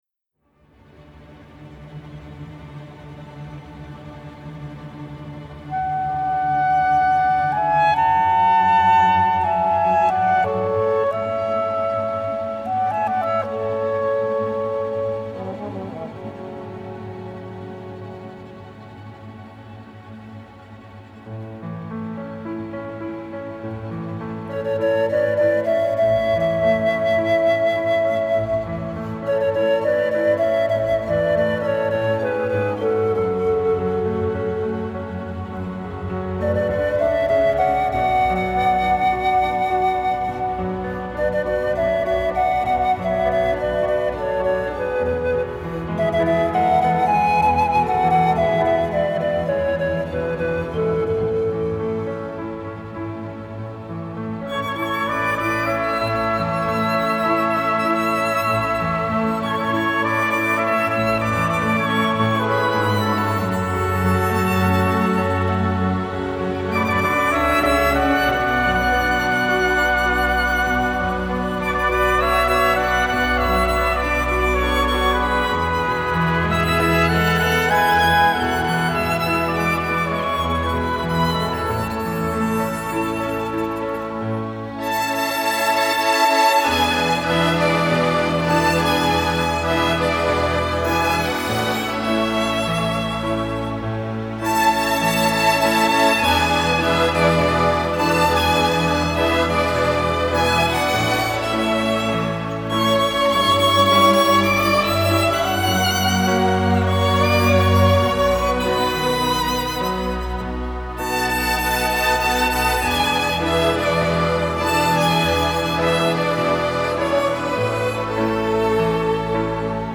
Genre: Pop, Instrumental, Easy Listening